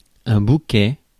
Ääntäminen
IPA: /bu.kɛ/